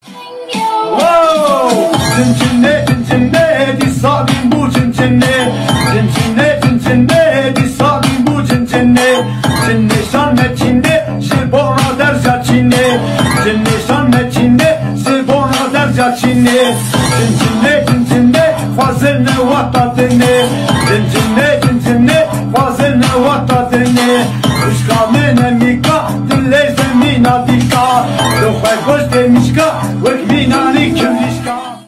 весёлые